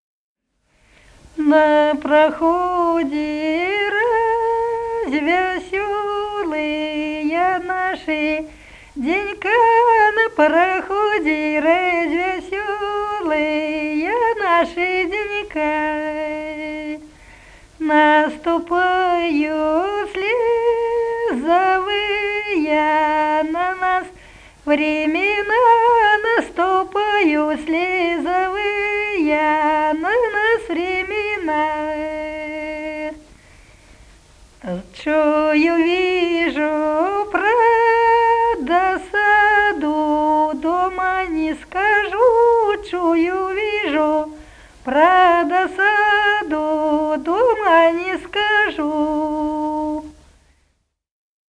Народные песни Смоленской области